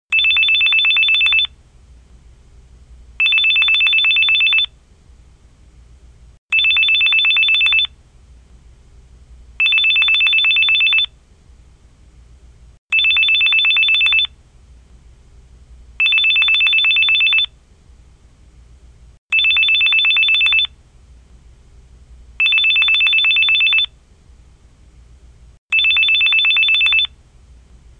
phone ring , electronic ringing